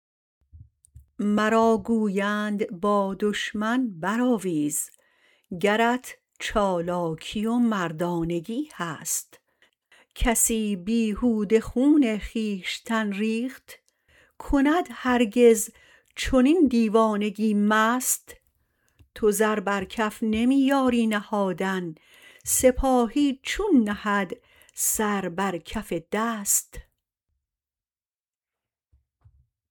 سعدی » مواعظ » قطعات » شمارهٔ ۱۴ با خوانش